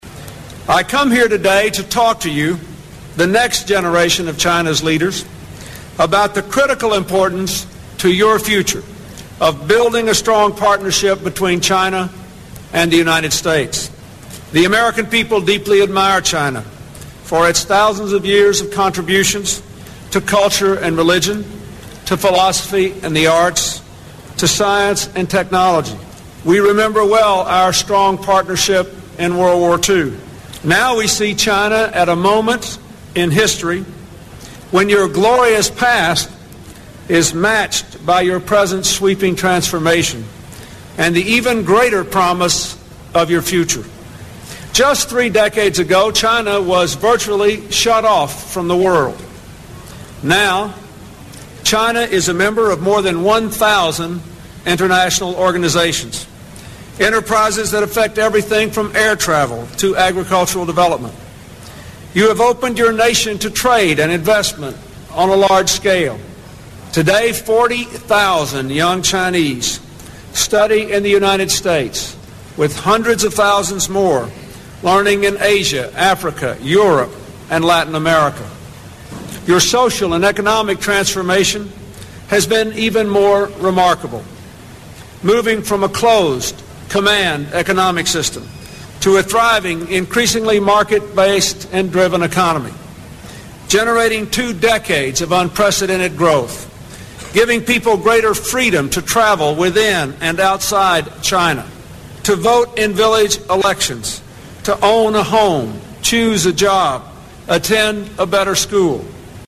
名校励志英语演讲 109:21世纪的挑战(3) 听力文件下载—在线英语听力室
借音频听演讲，感受现场的气氛，聆听名人之声，感悟世界级人物送给大学毕业生的成功忠告。